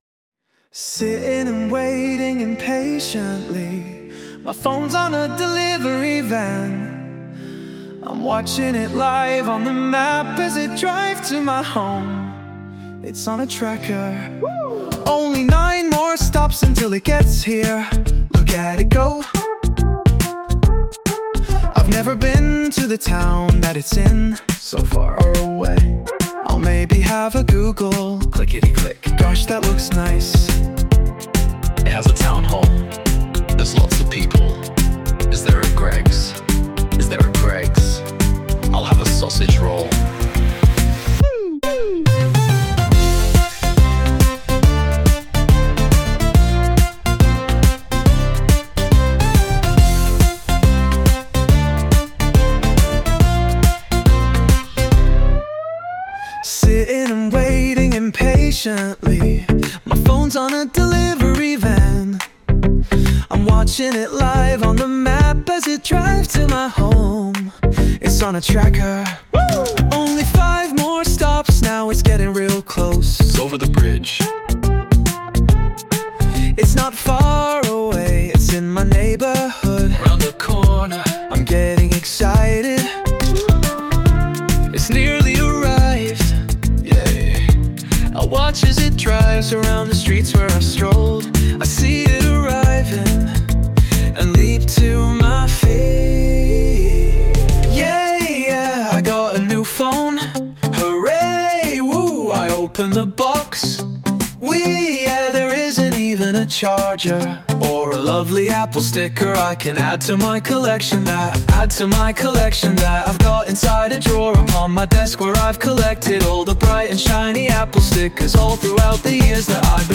Sound Imported : Transindental Pleasant Plucker
Sung by Suno